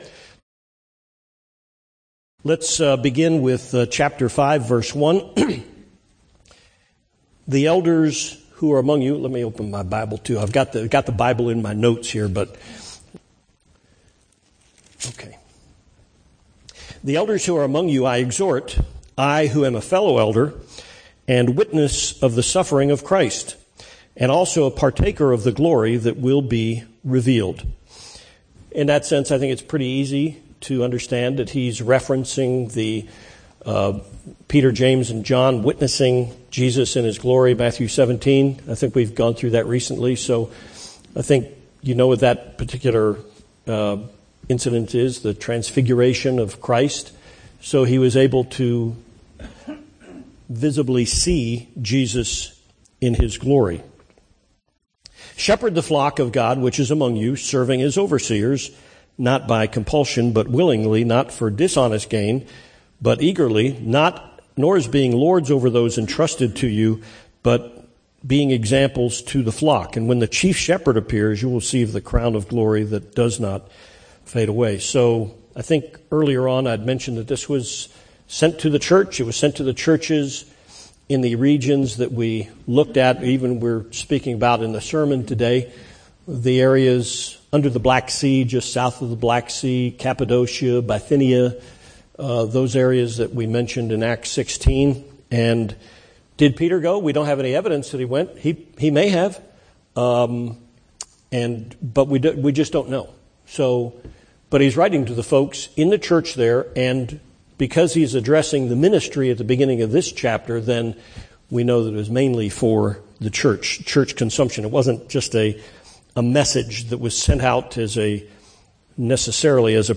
1 Peter Chapter 5 Study